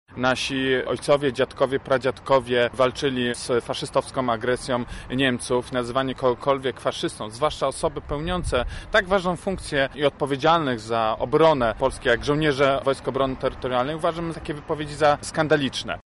O sytuacji mówi Jakub Kulesza przewodniczący koła poselskiego Konfederacji